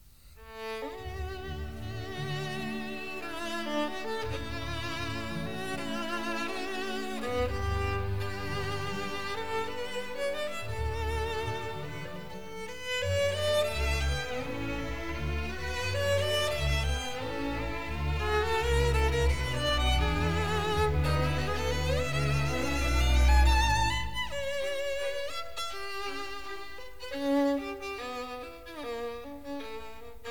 Recorded in the Kingsway Hall.
London in December 1956